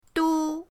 du1.mp3